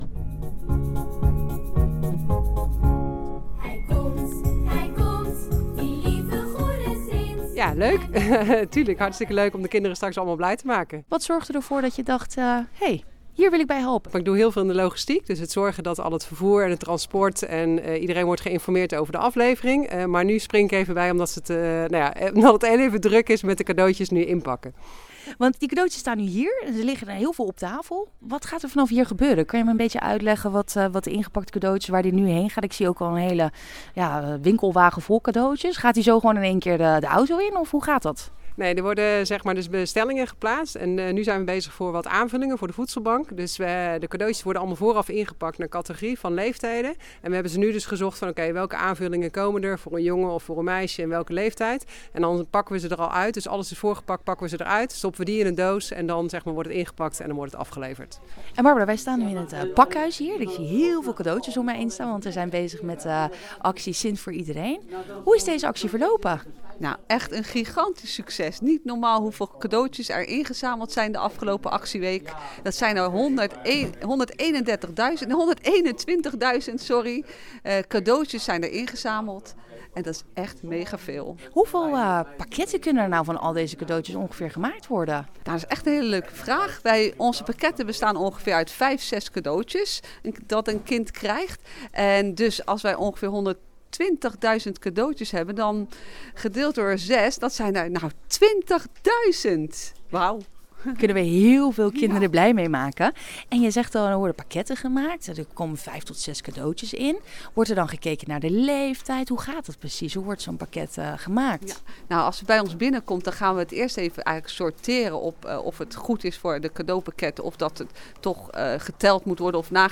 BO is langs in Voorburg langsgegaan om te zien wat er na de inzameling gebeurt. Hoe worden de cadeaus verdeeld en wie krijgt ze uiteindelijk?